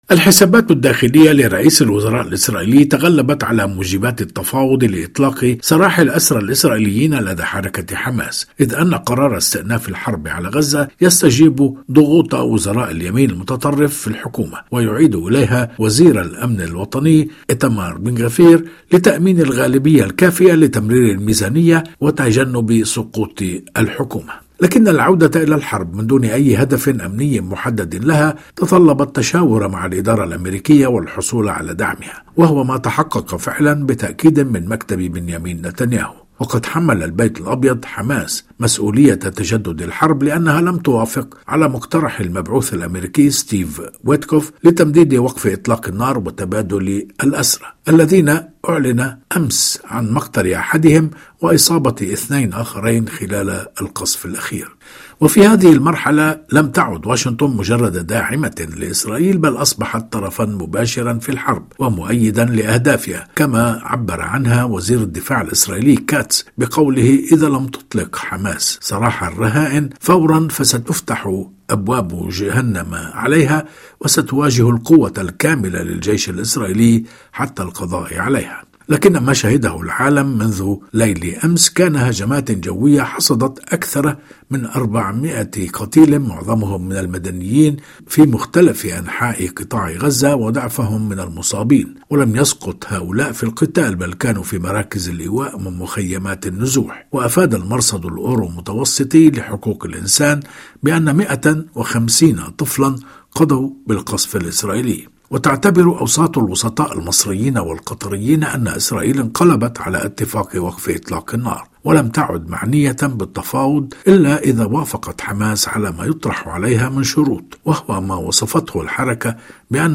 فقرة إخبارية تتناول خبراً أو حدثاً لشرح أبعاده وتداعياته، تُبَثّ على مدار الأسبوع عند الساعة الرابعة والربع صباحاً بتوقيت باريس ويُعاد بثها خلال الفترات الإخبارية الصباحية والمسائية.